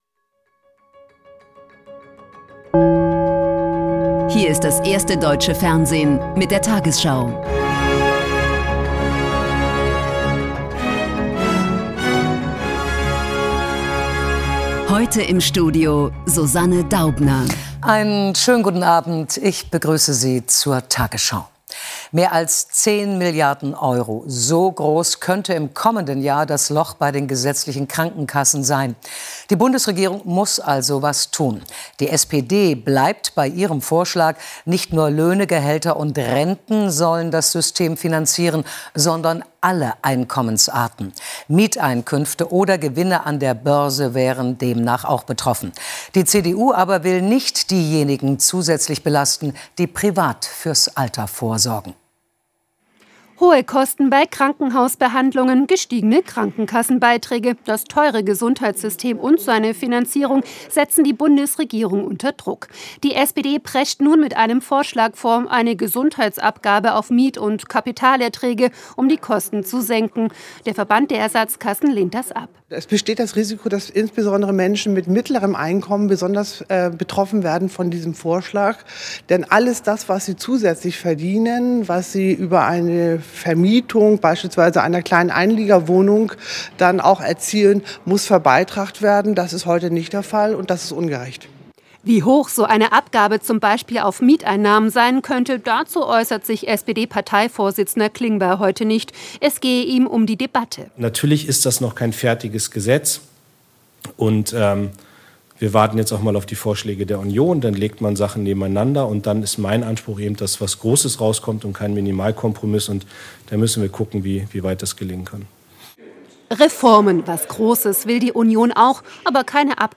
tagesschau 20:00 Uhr, 09.02.2026 ~ tagesschau: Die 20 Uhr Nachrichten (Audio) Podcast